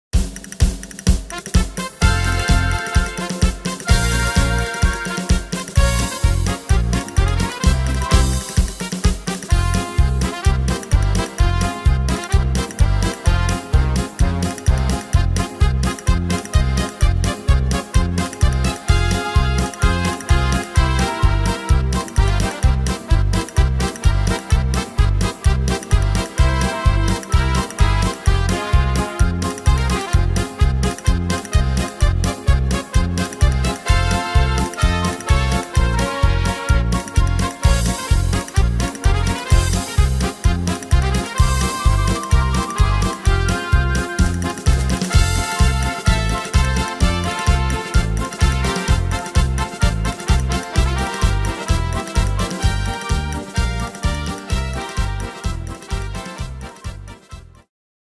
Tempo: 128 / Tonart: F-Dur